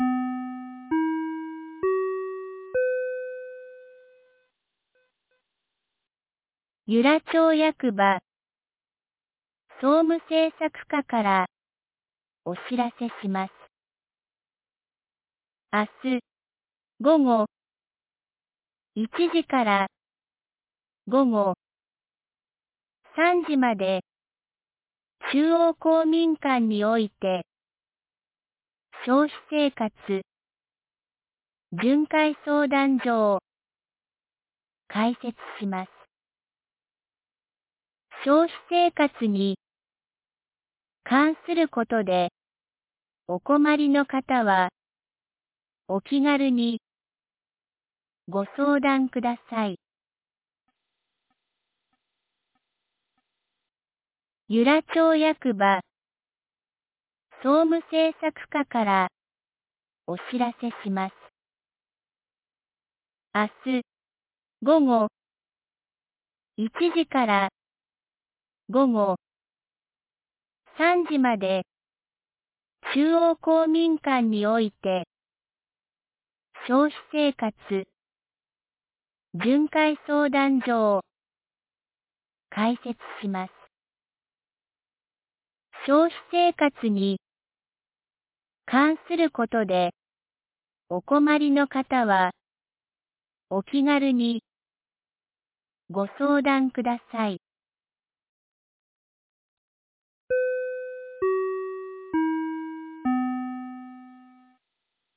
2026年02月02日 17時12分に、由良町から全地区へ放送がありました。